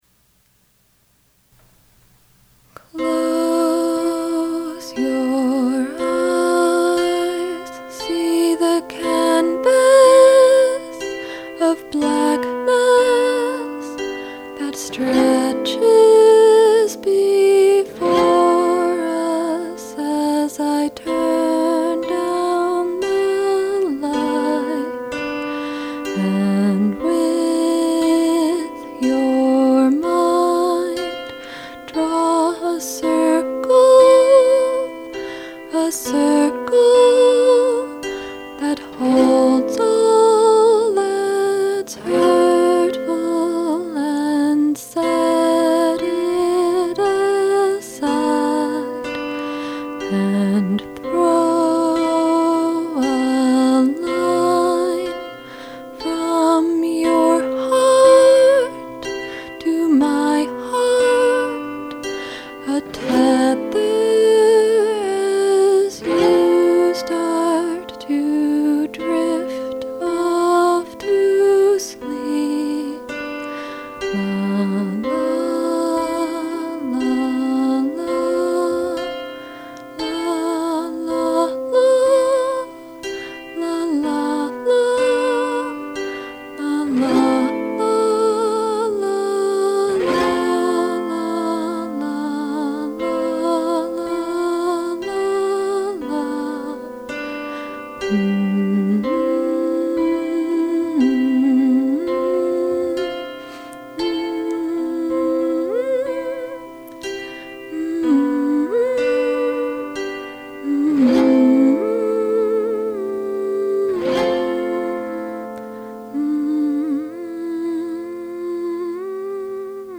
it's in c major
verse, verse, verse